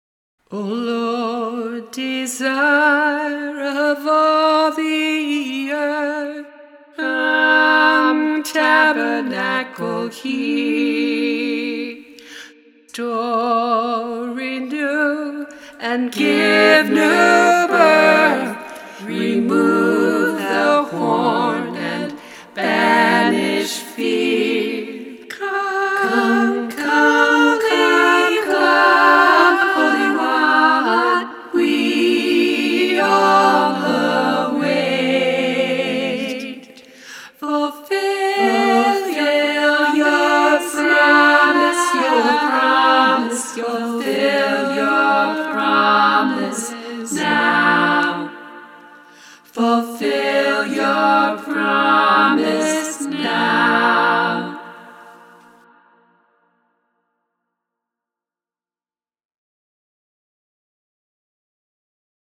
The angels were singing: